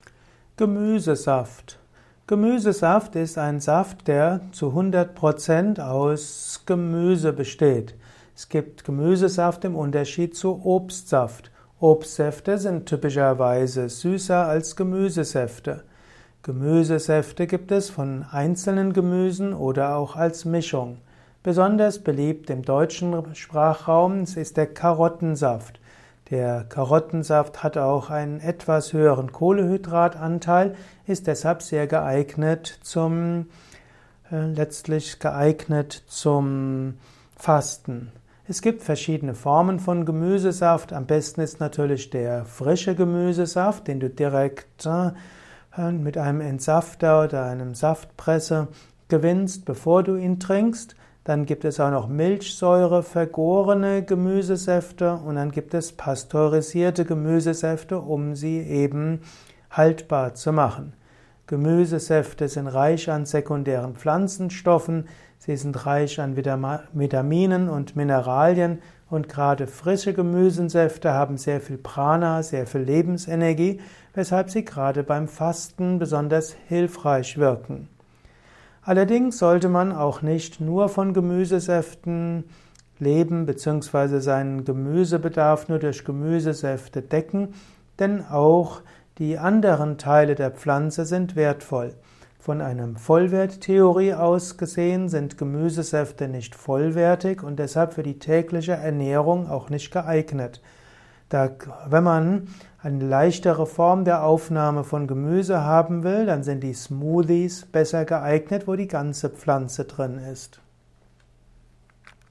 Ein Kurzvortrag über Gemüsesaft